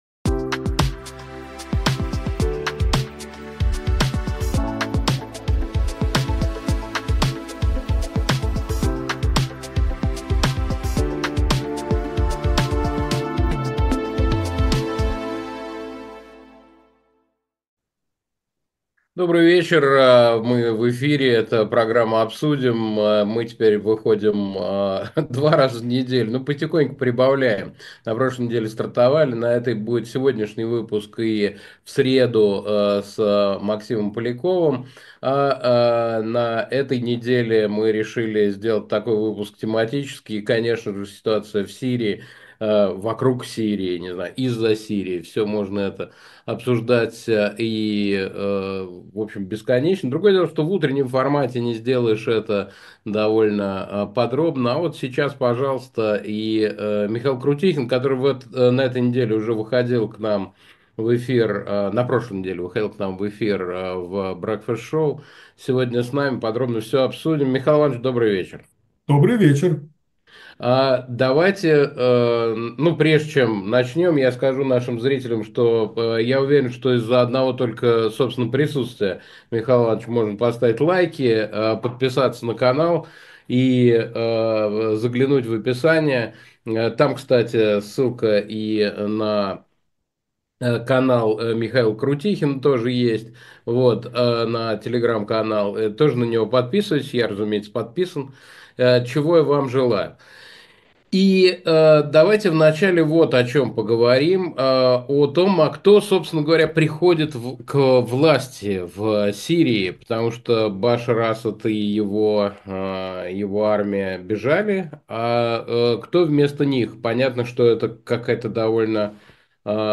Эфир ведёт Александр Плющев
В программе «Обсудим» говорим о самых важных событиях с нашими гостями.